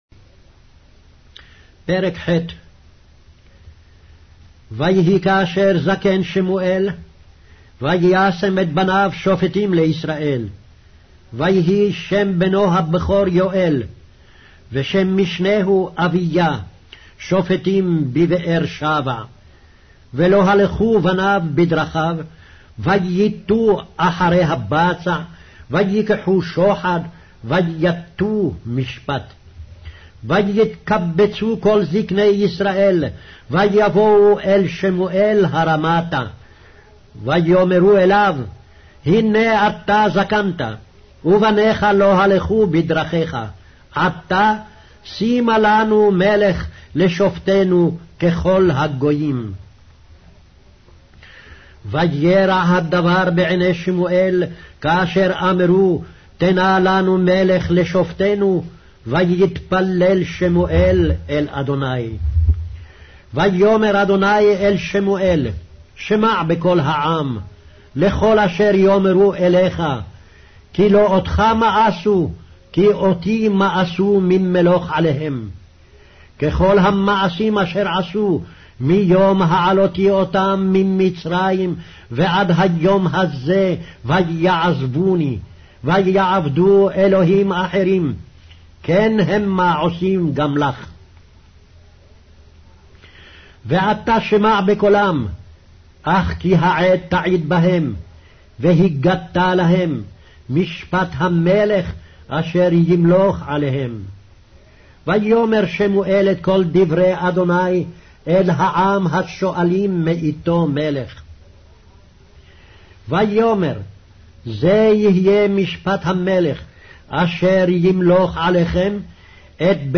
Hebrew Audio Bible - 1-Samuel 11 in Irvkn bible version